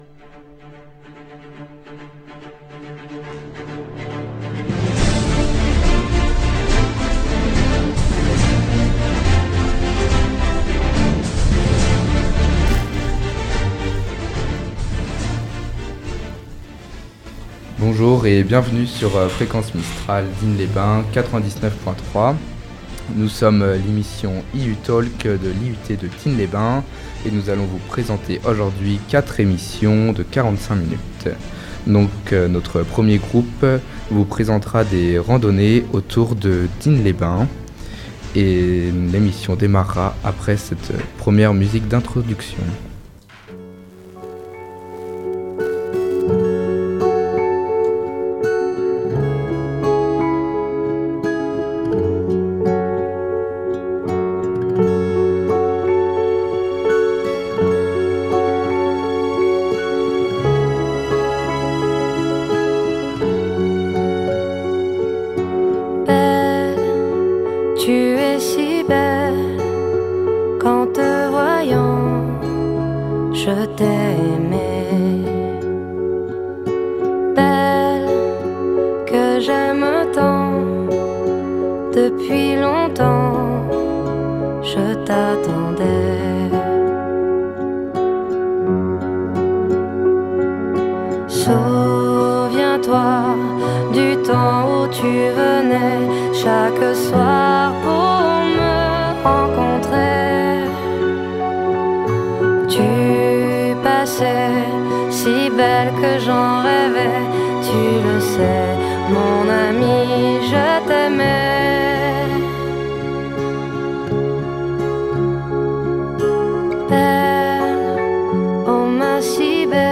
Une émission réalisée entièrement par des étudiantes, et étudiants de l'IUT d'Aix-Marseille Site de Digne-les-Bains saison 2023-2024, en 2ème année d'agronomie.